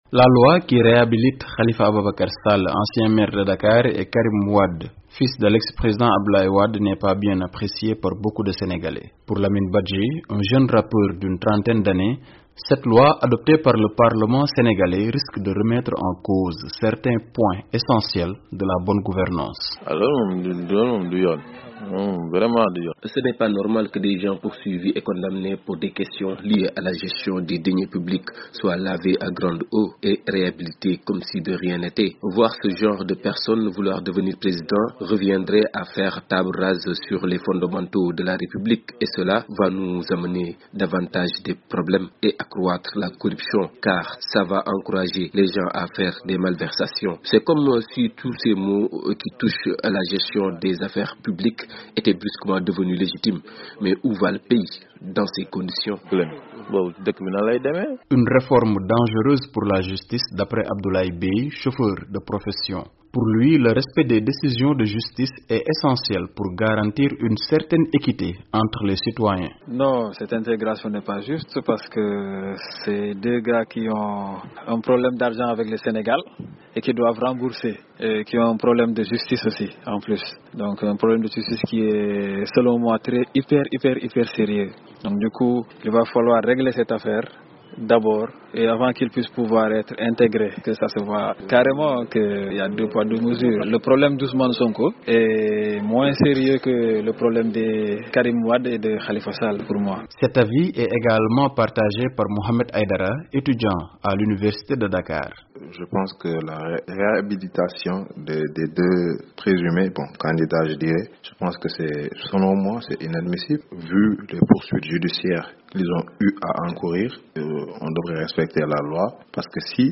Réactions des Sénégalais à une probable réhabilitation de l’ex maire de Dakar Khalifa Ababacar Sall et Karim Wade